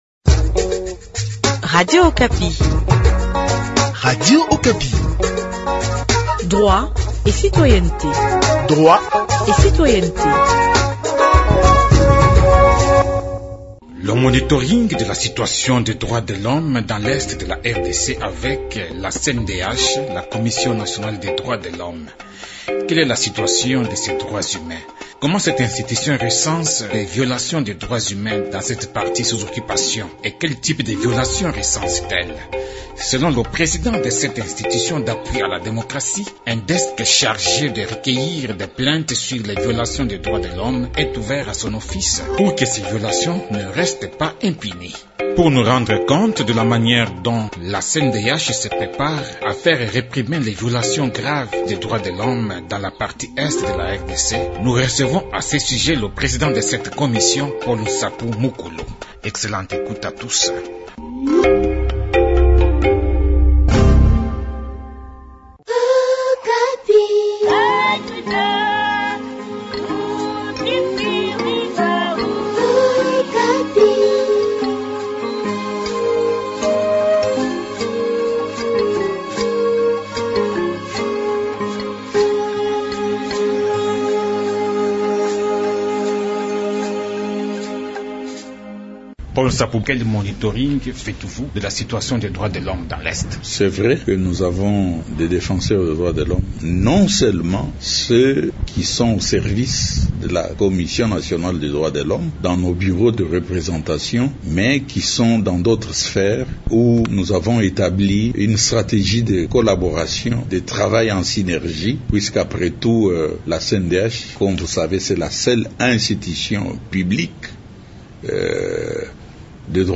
La Commission nationale des droits de l’homme ouvre un desk à son office pour enregistrer des plaintes contre les graves violations des droits de l’homme au Nord et Sud-Kivu. Pour se rendre compte de la manière dont cette commission citoyenne recense divers cas de violation des droits humains dans une zone à conflit militaire, son président fait avec nous le point du monitoring sur la situation des droits de l’homme dans cette zone.
Selon Paul Nsapu Mukulu, président de la Cndh qui répond à nos questions, ces violations ne resteront pas impunies et la CPI s’en est déjà saisie.